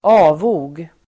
Ladda ner uttalet
Uttal: [²'a:vo(:)g]